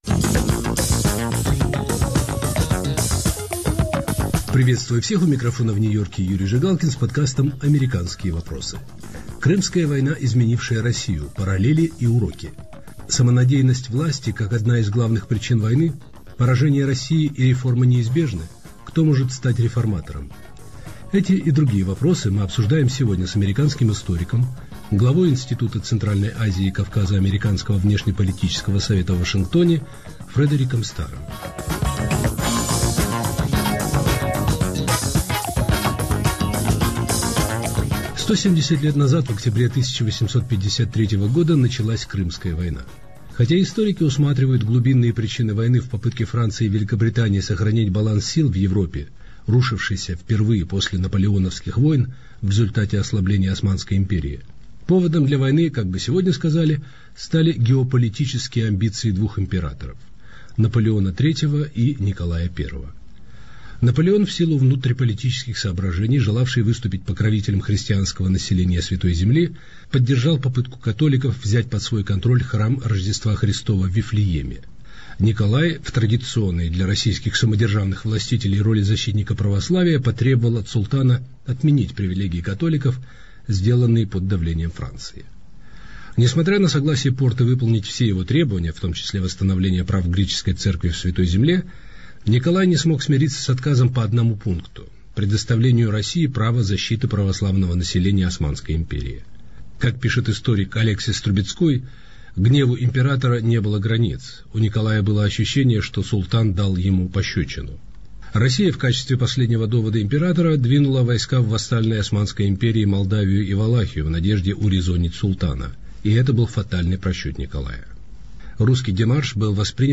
Уроки Крымской войны. Разговор с американским историком